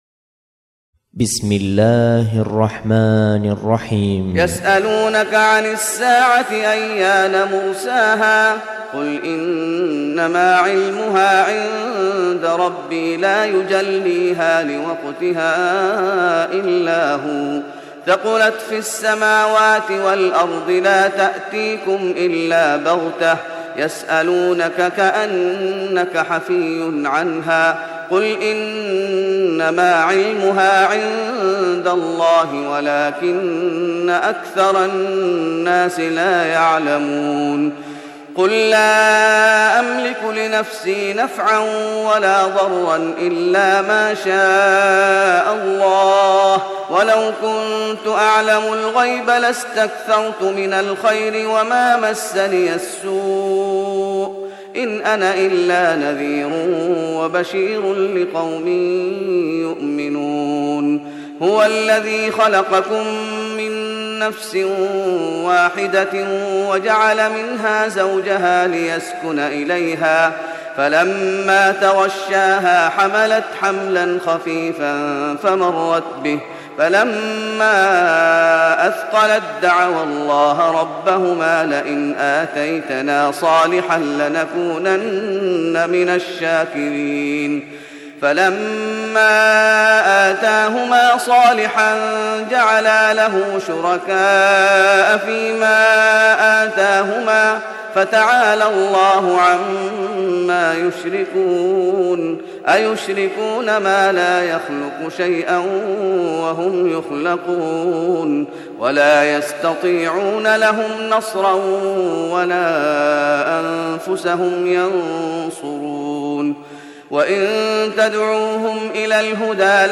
تهجد رمضان 1417هـ من سورة الأعراف (187-206) Tahajjud Ramadan 1417H from Surah Al-A’raf > تراويح الشيخ محمد أيوب بالنبوي 1417 🕌 > التراويح - تلاوات الحرمين